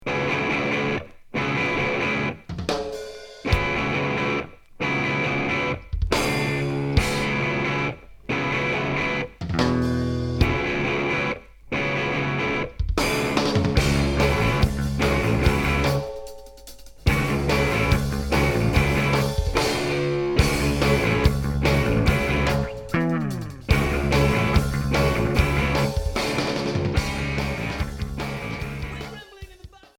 Noise